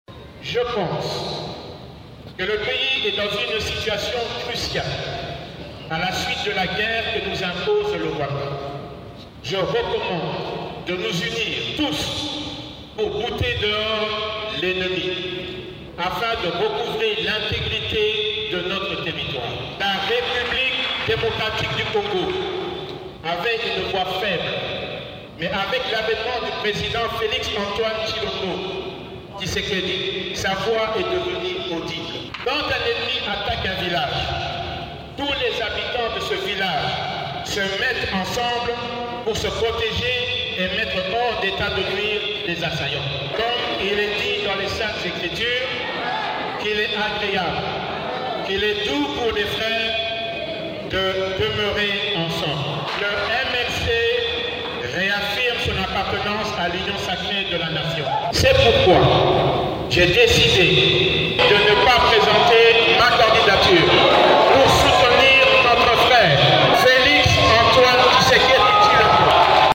A l’issue du 4e congrès de son parti, le Mouvement de libération du Congo (MLC), samedi 30 septembre à Kinshasa, Jean-Pierre Bemba a annoncé qu’il ne présenterait pas sa candidature à l'élection présidentielle de décembre 2023.
Ecoutez-le dans cet extrait sonore :